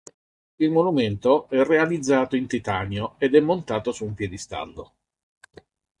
pie‧di‧stàl‧lo
/pje.disˈtal.lo/